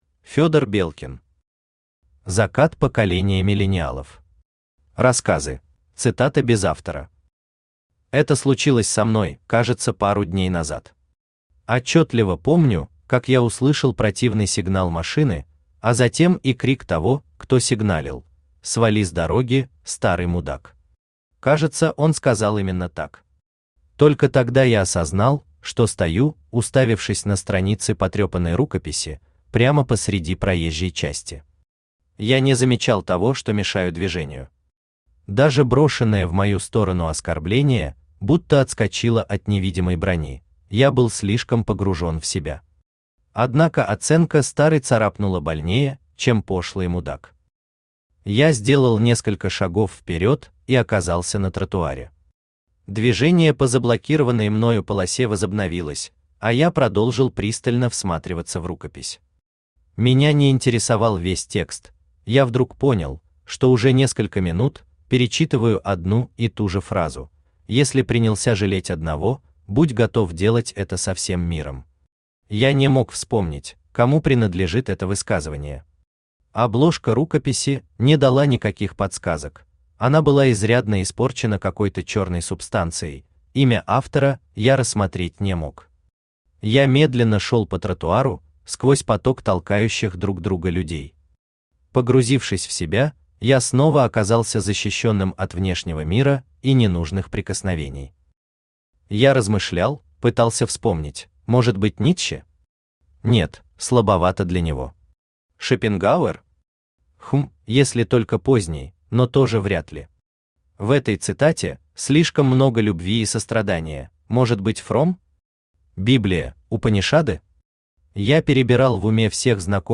Аудиокнига Закат поколения миллениалов. Рассказы | Библиотека аудиокниг
Рассказы Автор Федор Белкин Читает аудиокнигу Авточтец ЛитРес.